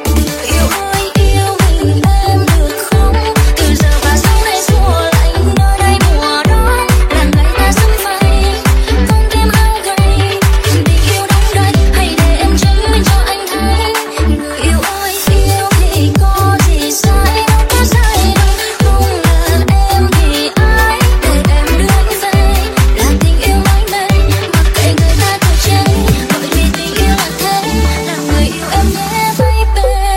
Remix TikTok